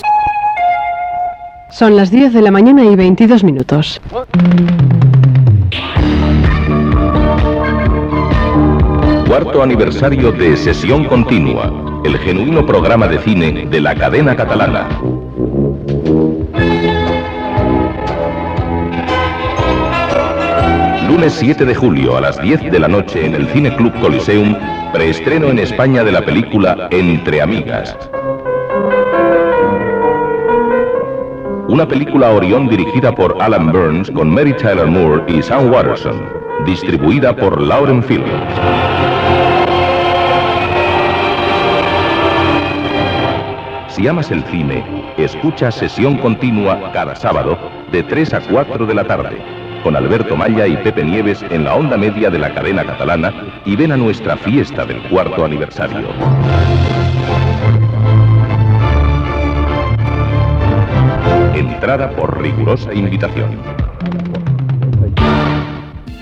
Hora exacta i promoció del 4rt aniversari del programa